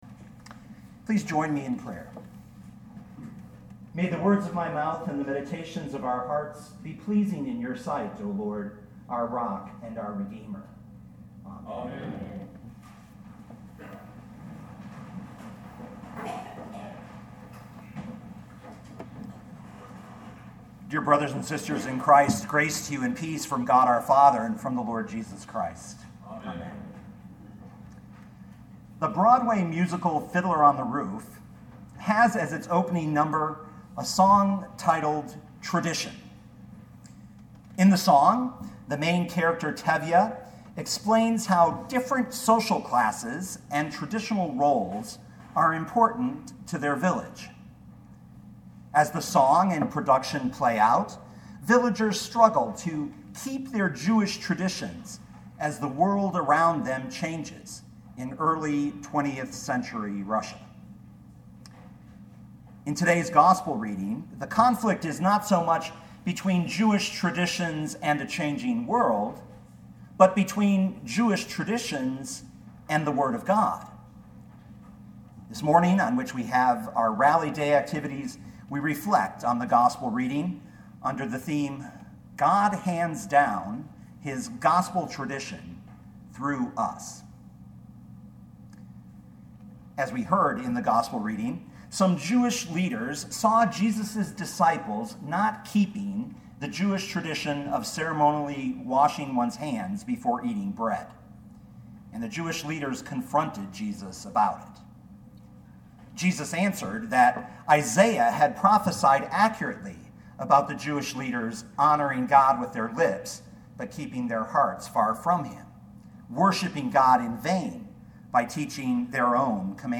2018 Mark 7:1-13 Listen to the sermon with the player below, or, download the audio.